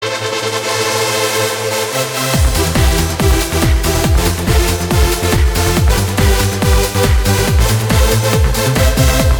I got it off some random amature DJ mix off the Internet